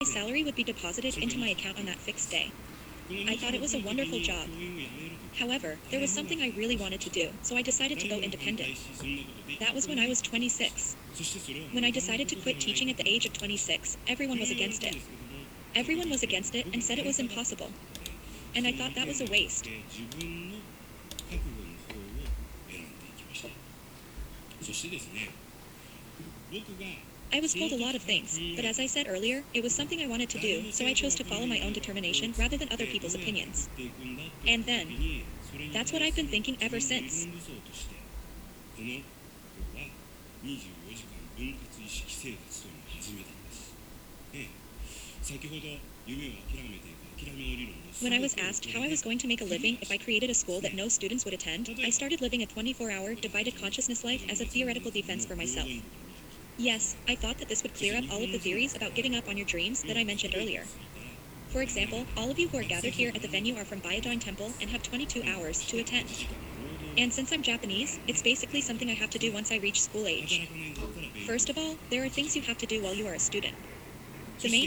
本モードの場合も、TEDの音声を翻訳させてみた。
※翻訳中のアプリ画面と、イヤホン音声の録音データを掲載している。